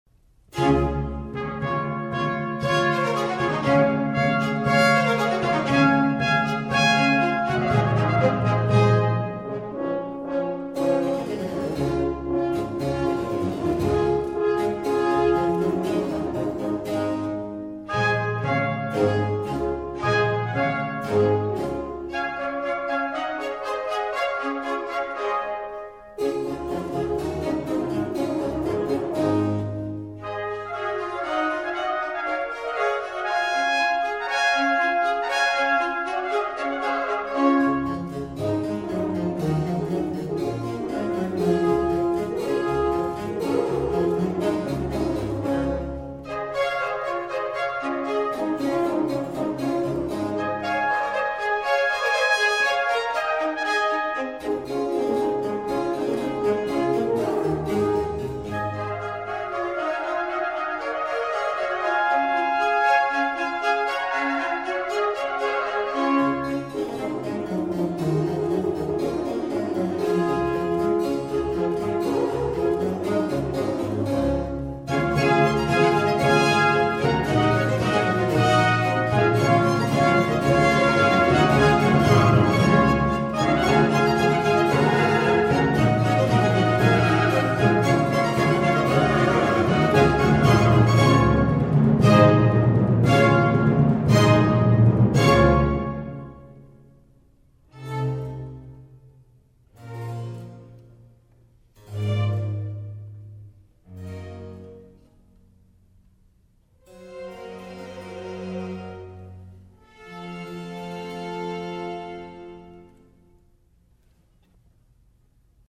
BAROQUE (1600-1750)
The Festive Baroque Orchestras had a jovial, grand, open and brilliant sound and it contained violins (divided into two groups, called violins 1 and violins 2), Violas, Cellos and Bass viol (playing the same music as the cellos an octave lower. To this was added 2 oboes, 3 trumpets, 2 timpani (kettledrums) and a keyboard instrument, generally a harpsichord.